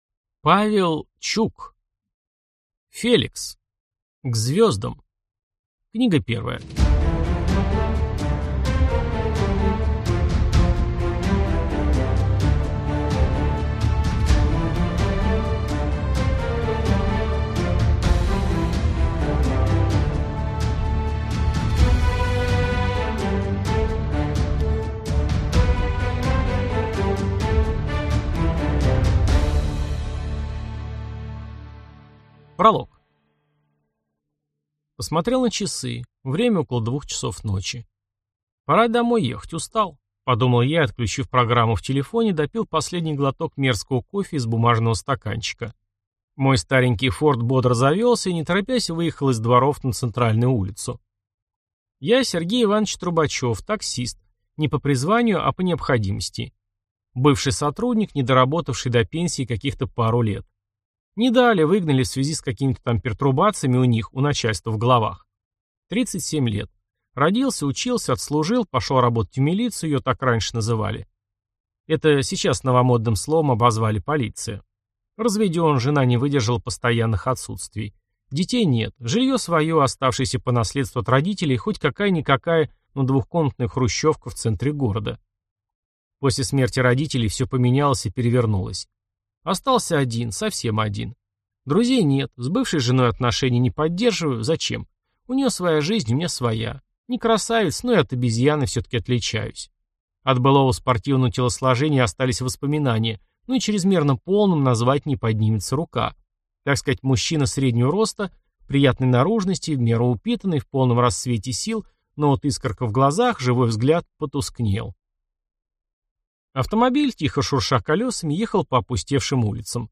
Аудиокнига Феликс. К звёздам | Библиотека аудиокниг
Прослушать и бесплатно скачать фрагмент аудиокниги